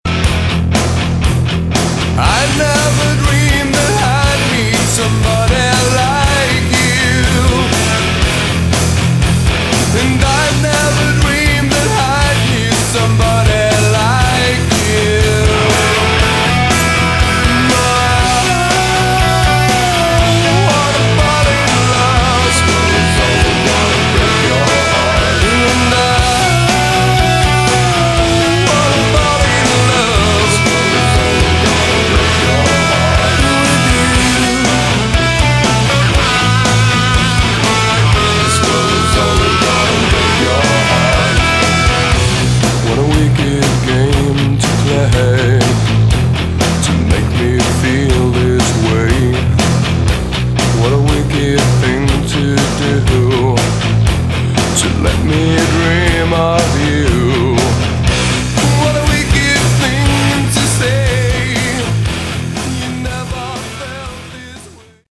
Category: Rock